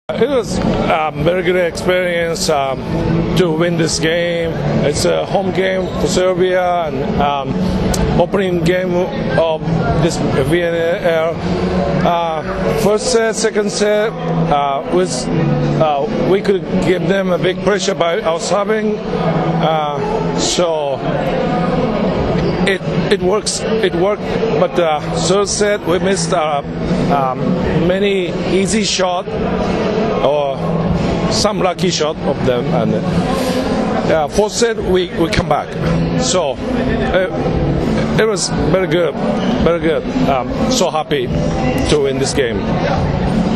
Izjava Juičija Nakagaičija